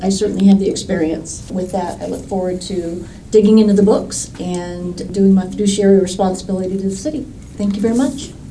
Muntin also took to the podium after being appointed, expressing her commitment to the position of City Treasurer.